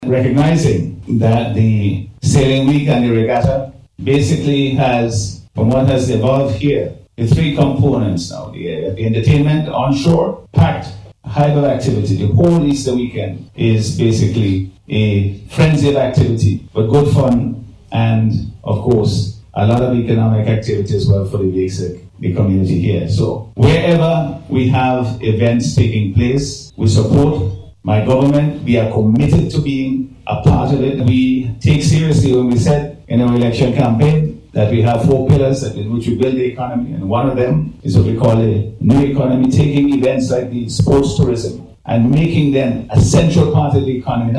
He made this statement during last Monday’s official launch of Sailing Week 2026, which runs from March 29th to April 6th.